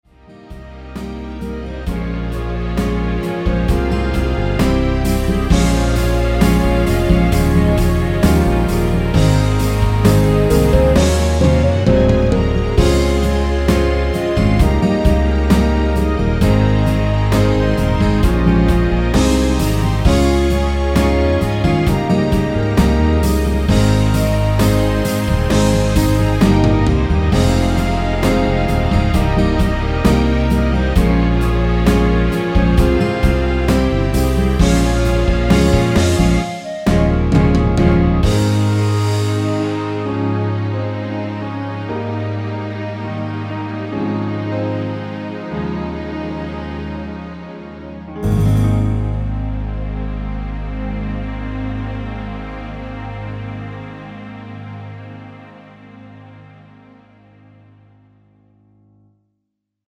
원키에서(-2)내린 멜로디 포함된 (짧은편곡) MR입니다.
F#
노래방에서 노래를 부르실때 노래 부분에 가이드 멜로디가 따라 나와서
앞부분30초, 뒷부분30초씩 편집해서 올려 드리고 있습니다.
중간에 음이 끈어지고 다시 나오는 이유는